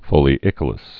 (fōlē-ĭkə-ləs)